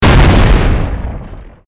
monster_atk_ground.mp3